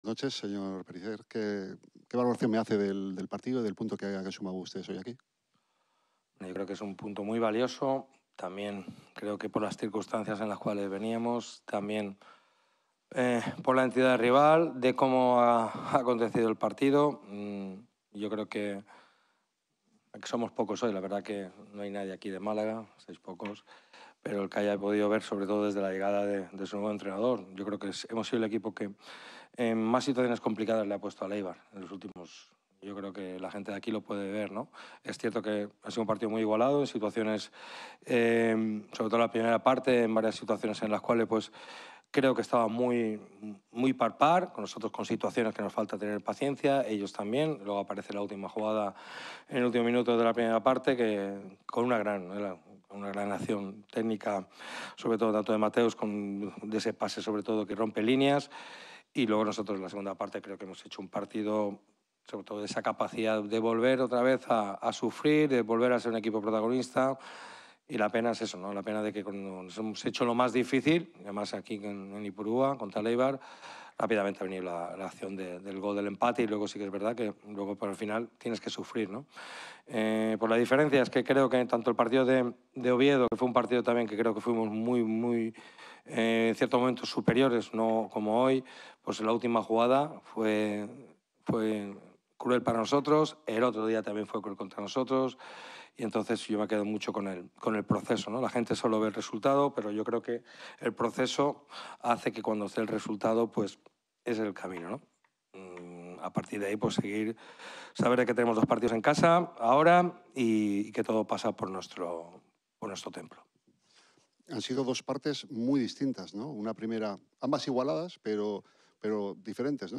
Rueda-de-prensa-SD-Eibar-vs-Malaga-CF.mp3